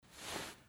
Sound effect
7.添加穿上行囊音效（衣服摩擦）
摩擦衣服 拾取东西.mp3